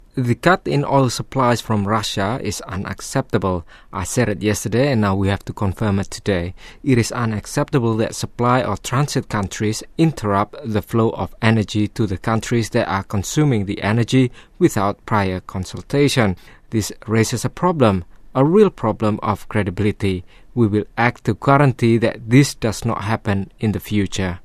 Indonesian, Male, 20s-40s